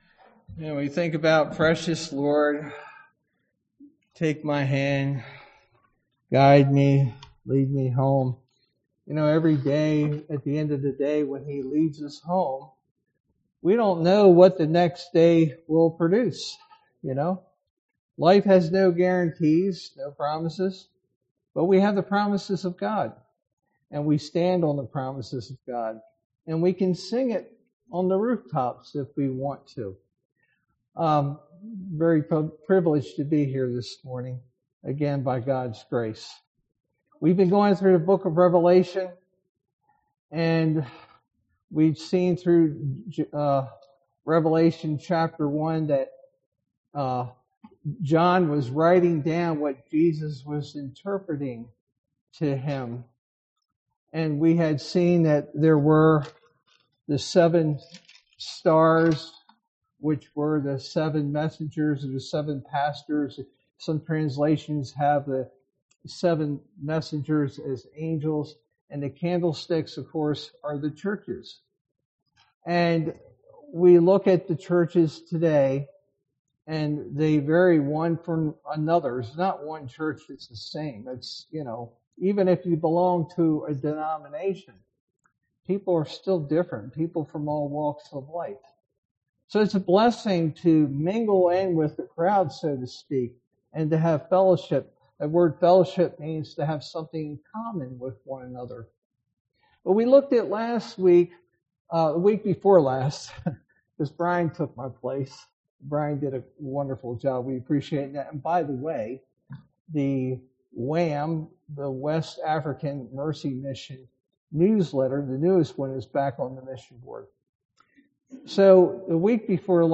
Sermon verse: Revelation 2:8-11